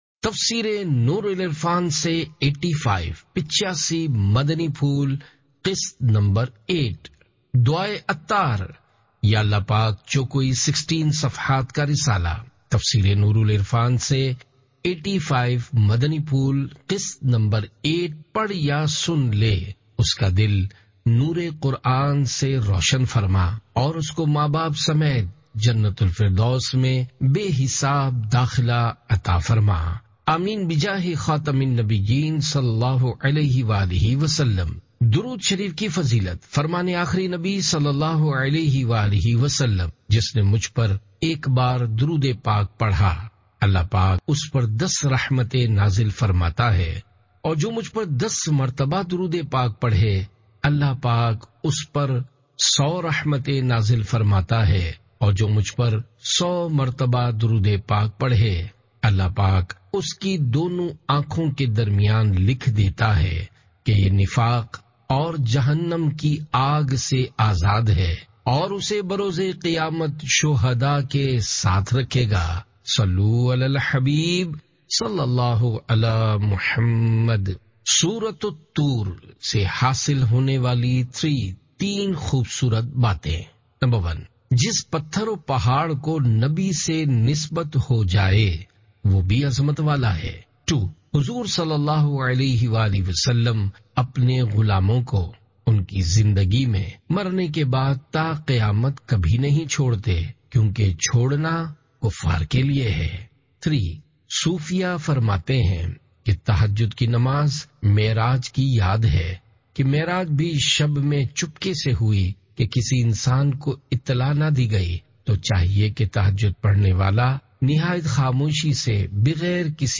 Audiobook – Tafseer e Noor ul Irfan Se 85 Madani Phool Qist-8 (Urdu)